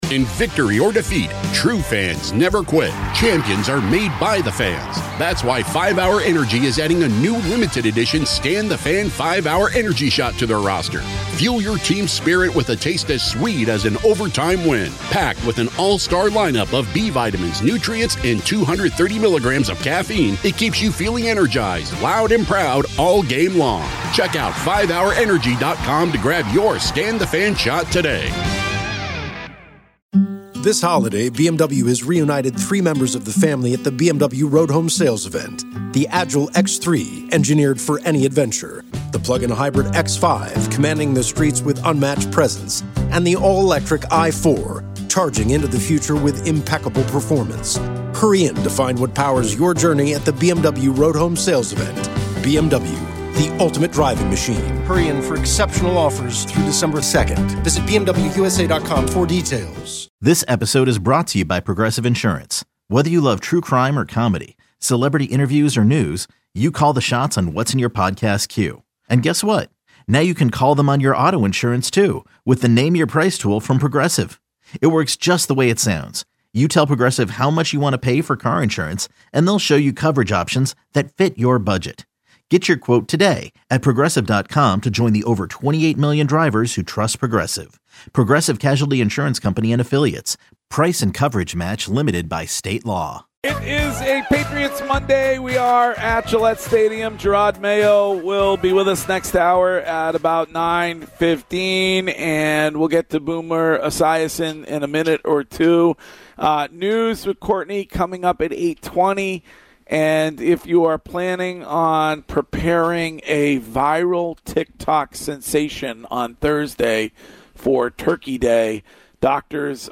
Boomer Esiason joins, says the Pats need to get free agency and draft right.